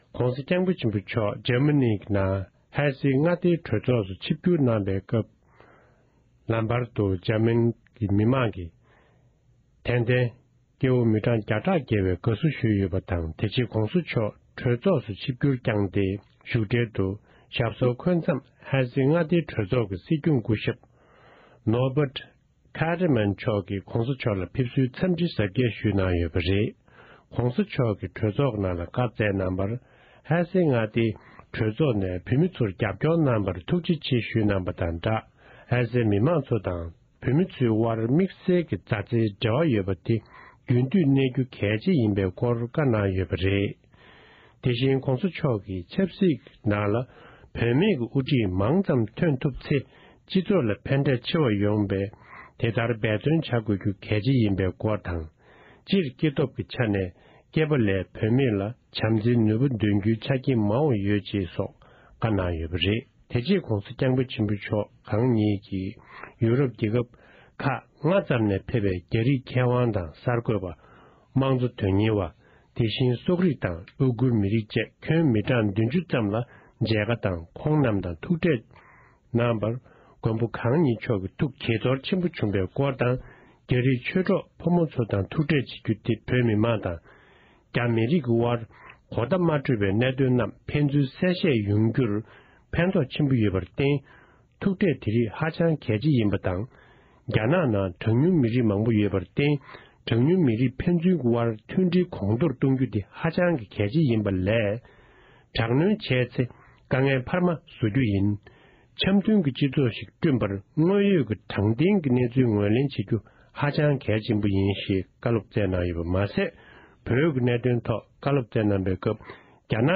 ཝིས་བ་དེན་ནས་གནས་ཚུལ་བཏང་འབྱོར་བྱུང་བར་གསན་རོགས༎